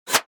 Download Free Paintball Sound Effects | Gfx Sounds
Paintballs-flying-by-fast-swish-whiz-6.mp3